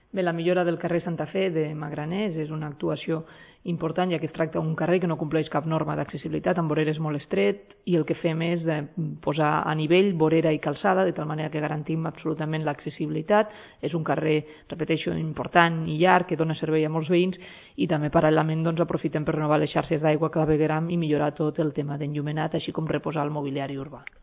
Compartir Facebook Twitter Whatsapp Descarregar ODT Imprimir Tornar a notícies Fitxers relacionats Tall de veu 1a tinent d'alcalde i regidora d'Urbanisme, Marta Camps (757.0 KB) T'ha estat útil aquesta pàgina?
tall-de-veu-1a-tinent-dalcalde-i-regidora-durbanisme-marta-camps